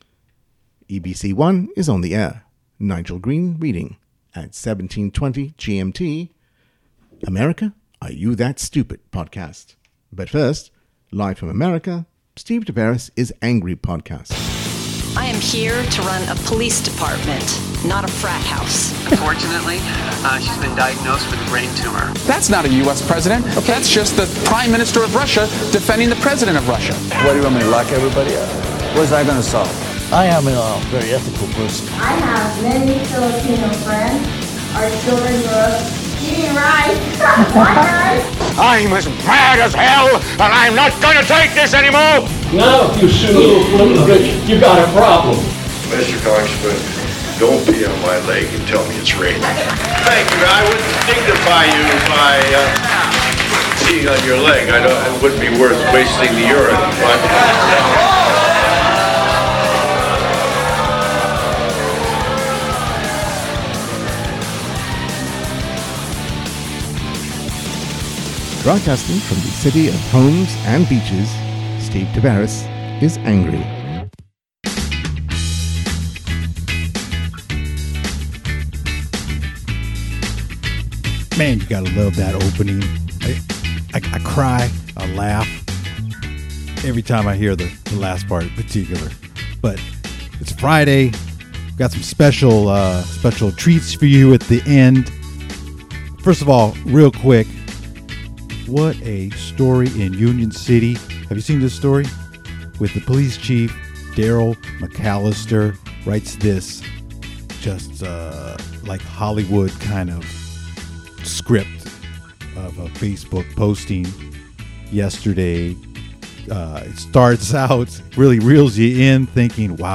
Aisha Wahab joins the Hot Seat for an interview about her vision for Hayward.